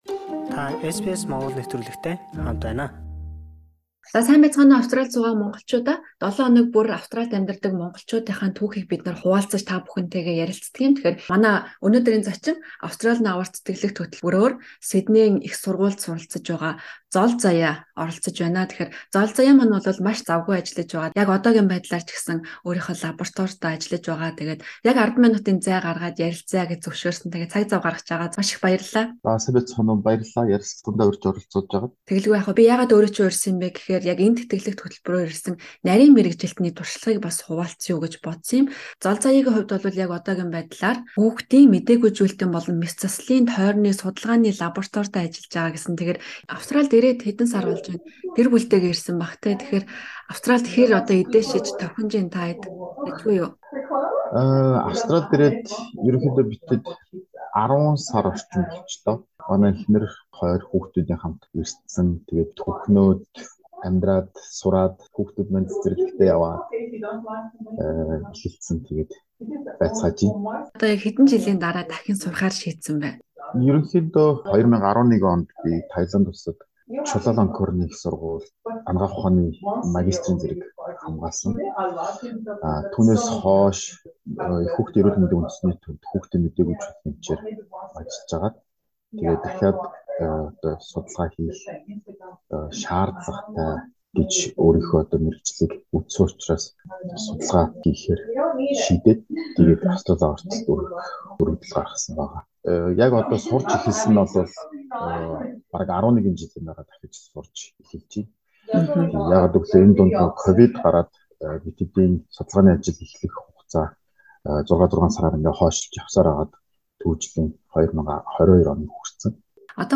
Түүнийг лабораторидоо тун завгүй ажиллаж байхад нь цөөн хором ярилцлаа.